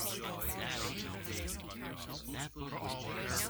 BackSound0087.wav